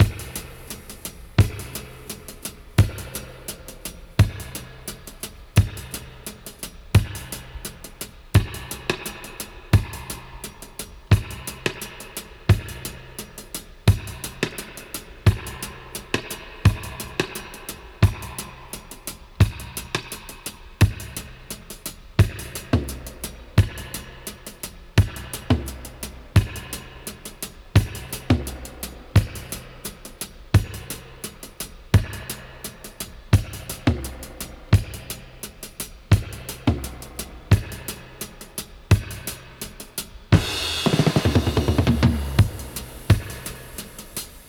85-DUB-02.wav